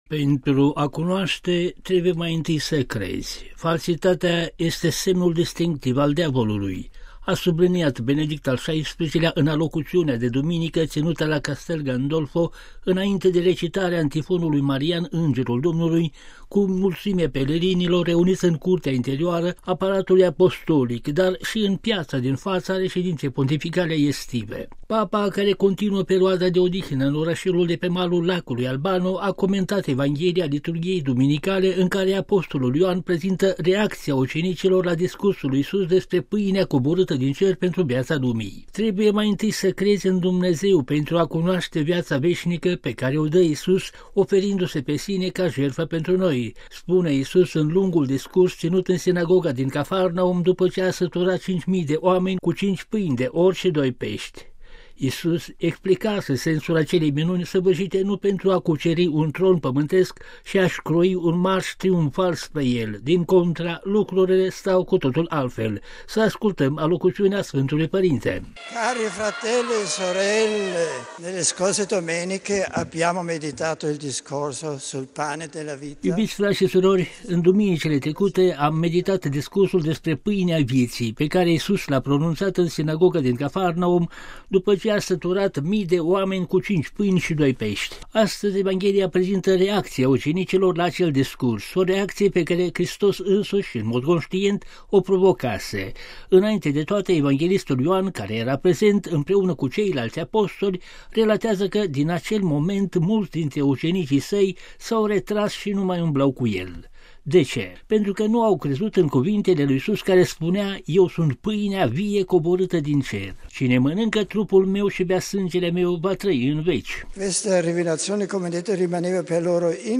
(RV - 26 august 2012) Pentru a cunoaşte trebuie mai întâi să crezi; falsitatea este semnul distinctiv al diavolului: a subliniat Benedict al XVI-lea în alocuţiunea de duminică ţinută la Castel Gandolfo înainte de recitarea antifonului marian „Îngerul Domnului” cu mulţimea pelerinilor reuniţi în curtea interioară a Palatului Apostolic dar şi în piaţa din faţa reşedinţei pontificale estive.
După rugăciunea cu pelerinii, Papa a adresat saluturi în diferite limbi primite cu entuziasm de grupurile prezente.